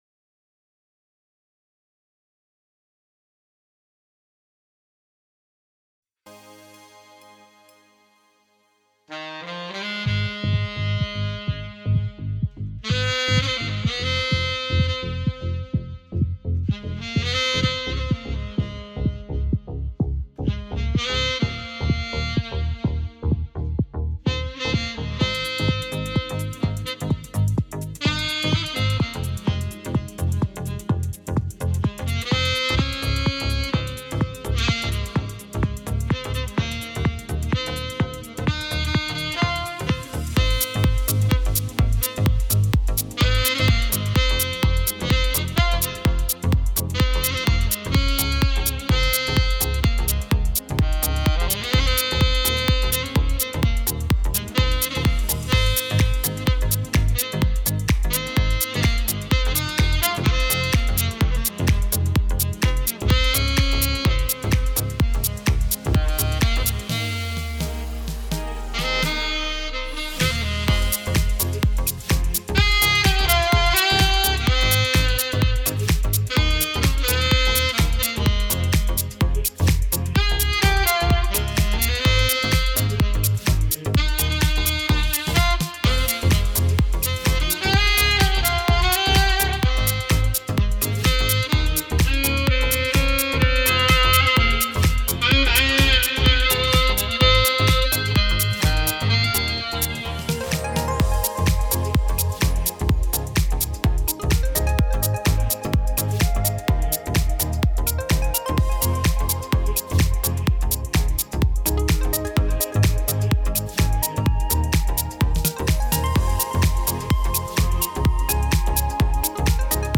Ibiza mix